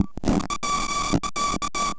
Abstract Rhythm 30.wav